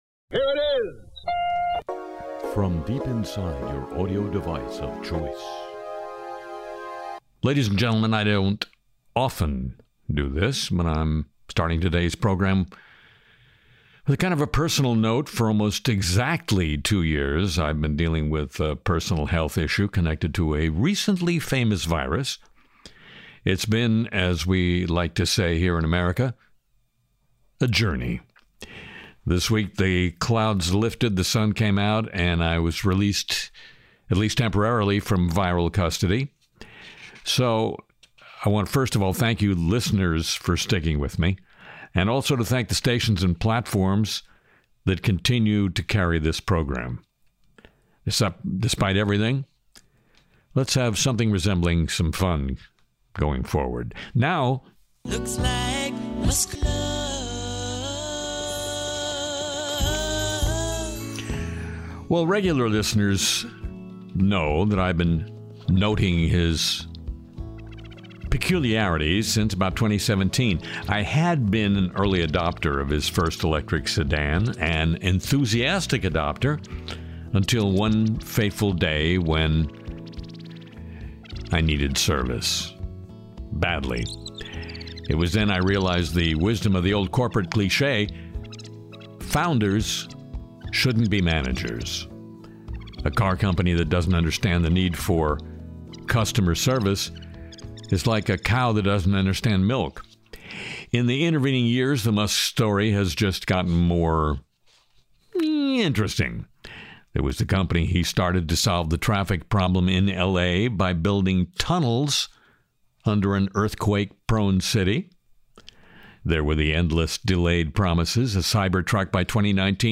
Part-time New Orleans resident Harry Shearer hosts a look at the worlds of media, politics, cyberspace, sports and show business while providing an eclectic array of music along the way.